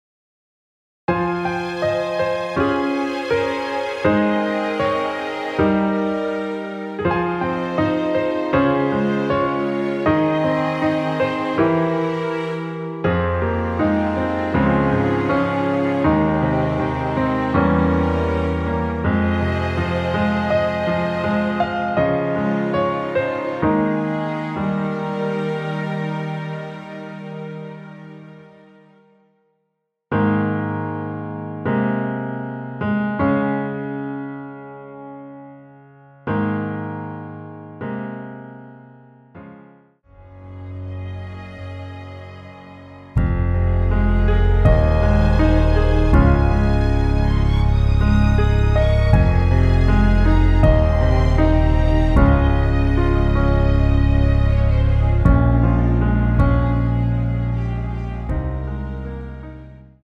Ab
앞부분30초, 뒷부분30초씩 편집해서 올려 드리고 있습니다.